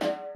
Shots Wolf (4).wav